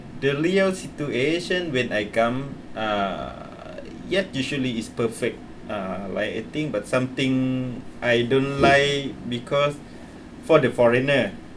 S1 = Brunei female S2 = Laos male
Intended Word: real Heard as: leal
There is [l] instead of [r] at the beginning of real .
But it is significant that this occurred near the start of the conversation, before she had got used to S2's patterns of pronunciation.